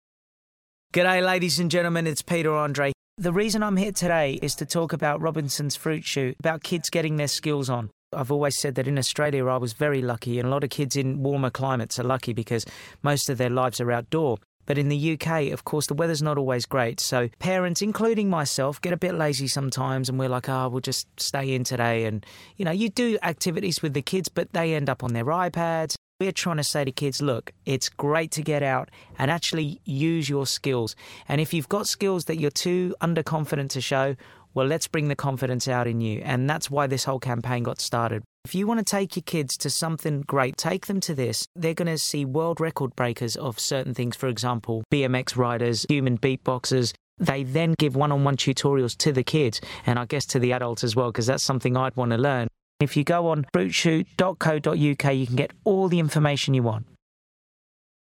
Peter Andre joined us in the On Broadcast studios to talk about the essential cool skills needed to impress in the playground which are revealed in new research released today by Robinsons Fruit Shoot. More than half (52%) of 6 to 11 year olds placed Street Dance at the top of the cool skills list, with Skateboarding clinching second place (39%) and Free Running taking third (27%).